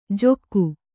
♪ jokku